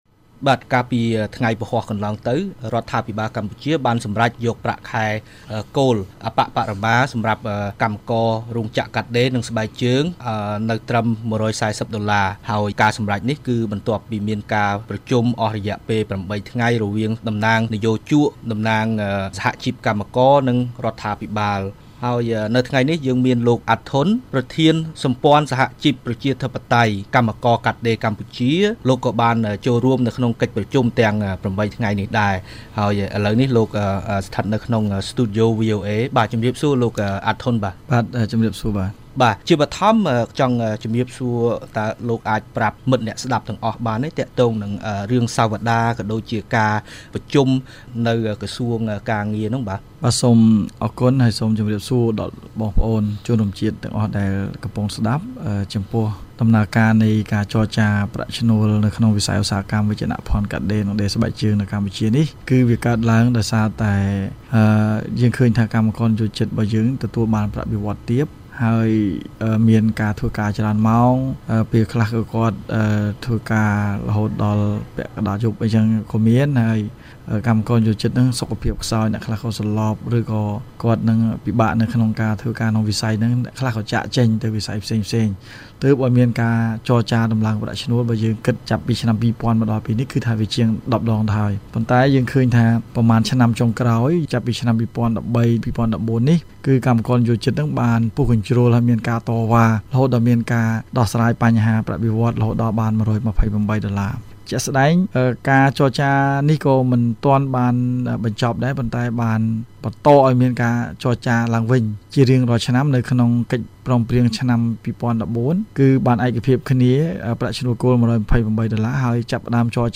បទសម្ភាសន៍៖ ការចរចាដំឡើងប្រាក់ខែដល់កម្មករមានភាពតានតឹង